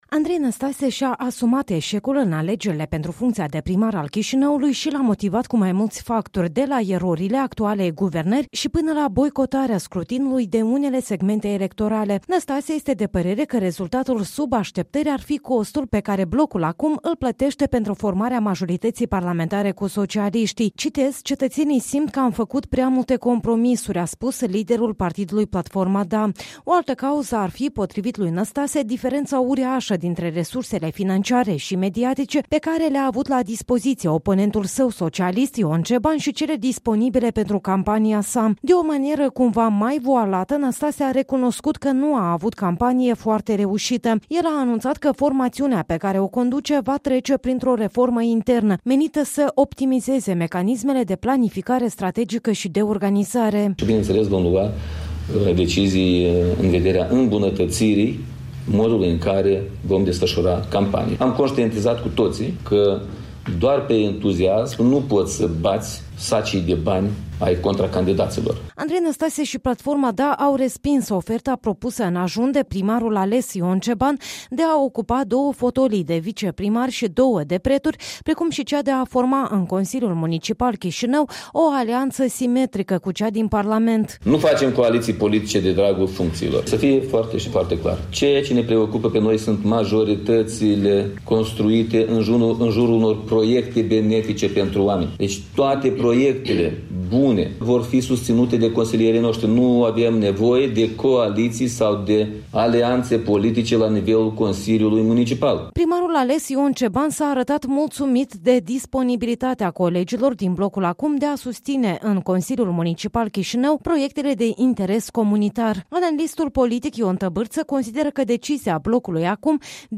Andrei Năstase, conferință de la presă la Chișinău, 5 noiembrie 2019